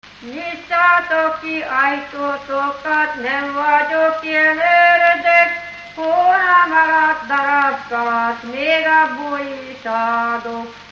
Felföld - Nyitra vm. - Menyhe
ének
Műfaj: Lakodalmas
Stílus: 7. Régies kisambitusú dallamok